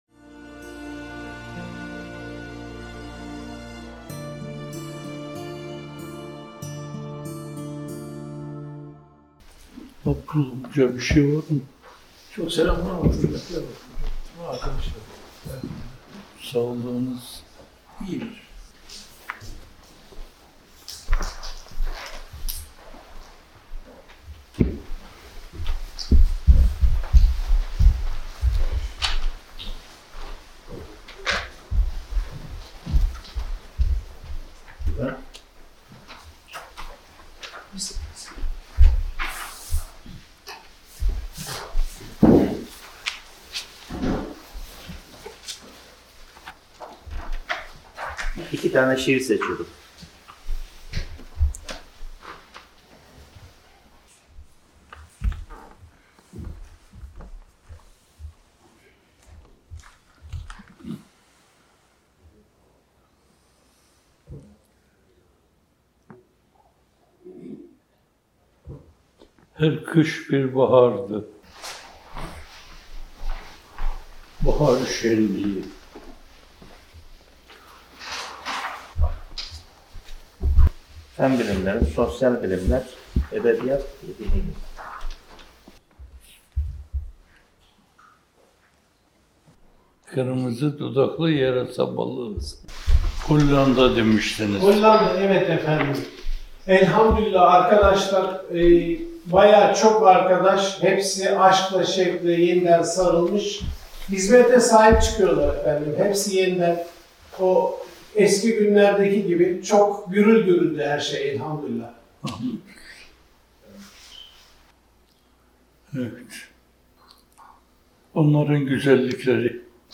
Muhterem M. Fethullah Gülen Hocaefendi’nin 25 Eylül 2024 tarihinde son kez iştirak ettikleri Çağlayan Dergisi mizanpajı.